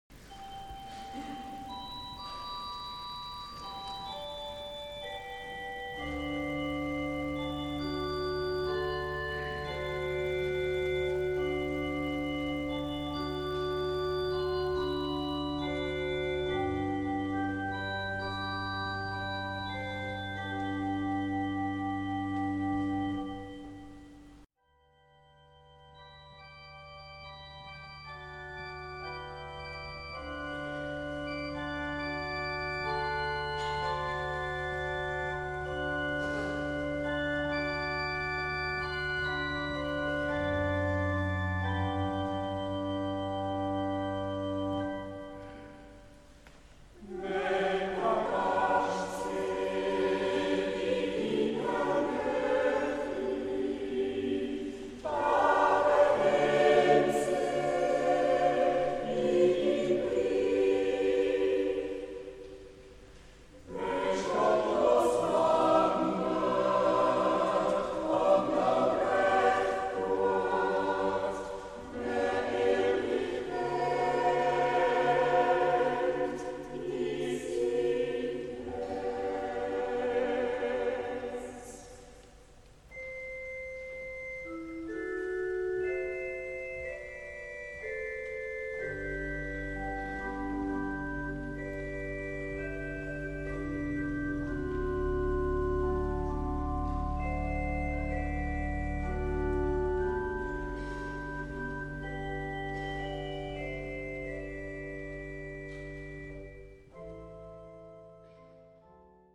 (Dialekt)
Diese Lieder sind auch als Naturjodel singbar.
ChorOrgel
Chor